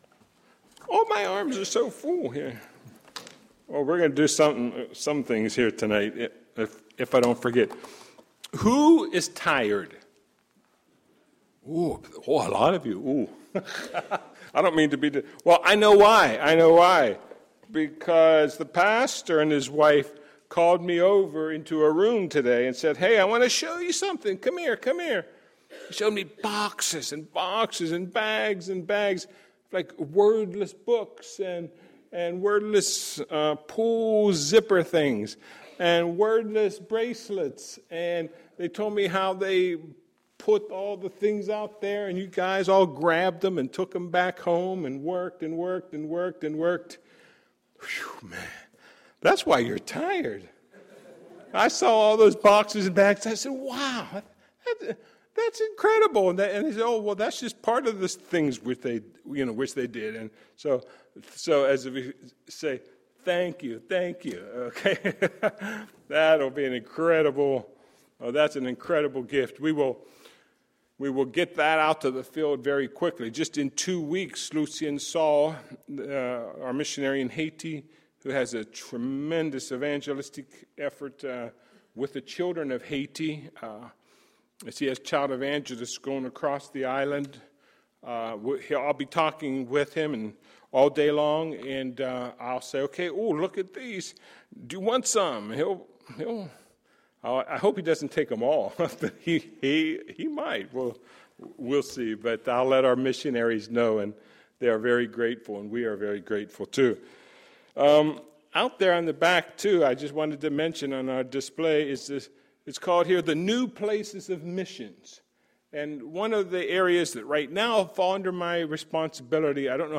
Tuesday, September 25, 2012 – Tuesday PM Session
Sermons